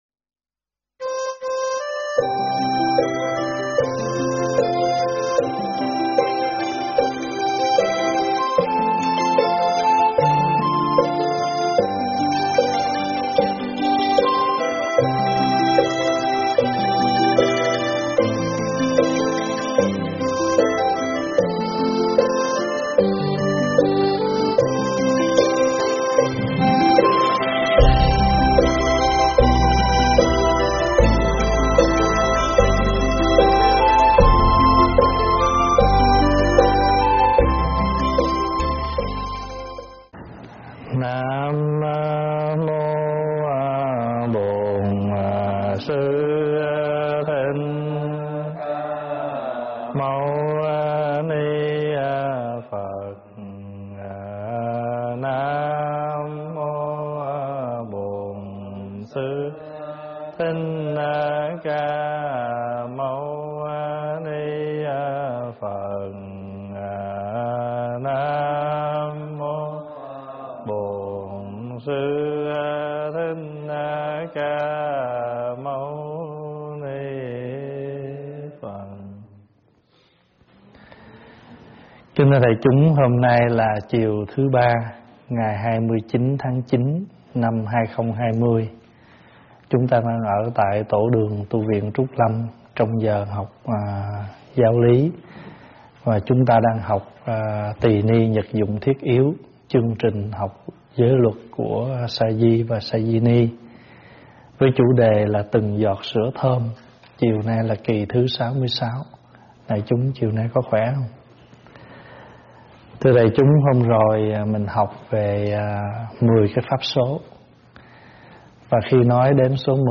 Nghe pháp âm Từng Giọt Sữa Thơm 66 - Giới tướng của thích xoa ma na - ĐĐ. Thích Pháp Hòa giảng tại Tv Trúc Lâm, Ngày 29 tháng 9 năm 2020
Thích Pháp Hòa giảng tại Tv Trúc Lâm, Ngày 29 tháng 9 năm 2020 Mp3 Thuyết Pháp Thích Pháp Hòa 2020 Thuyết Pháp Thích Pháp Hòa